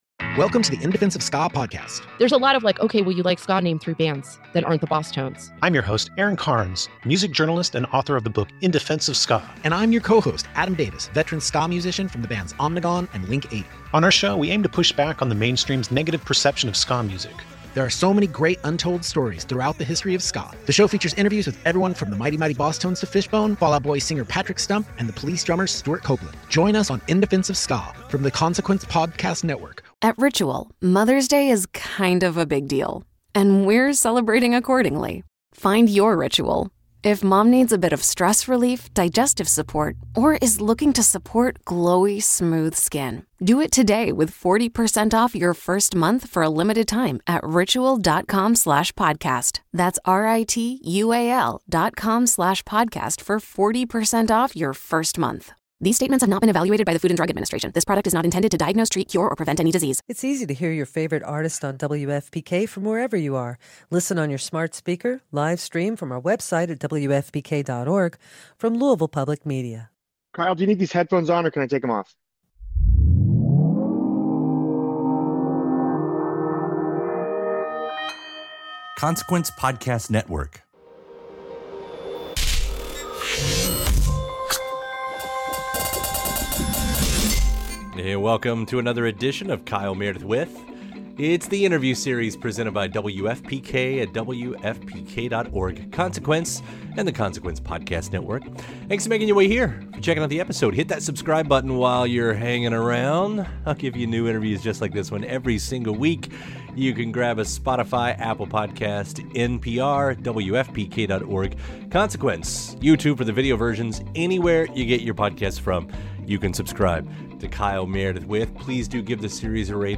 Listen to these archival interviews now.